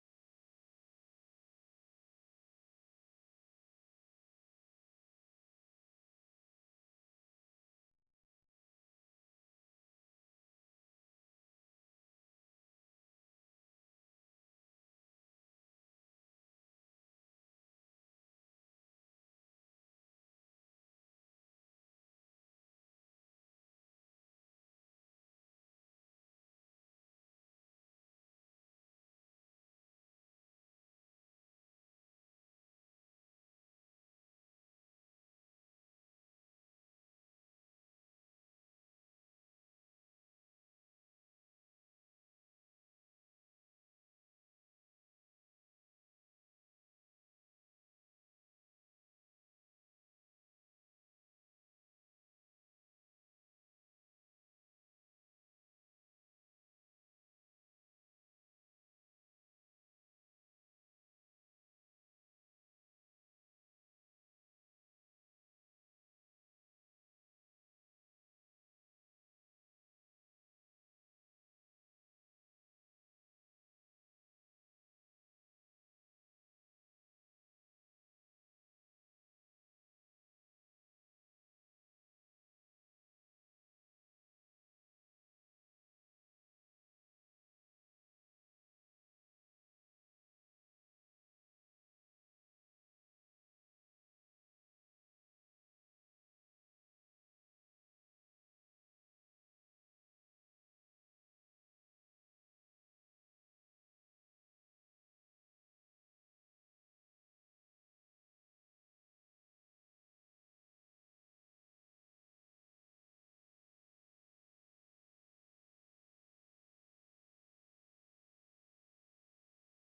را که در یکی از ماه‌های رمضان دهه شصت در مهدیه تهران ایراد شده است، می‌شنوید.
مصیبت‌خوانی کمتر شنیده‌شده